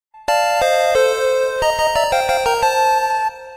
goal_complete.ogg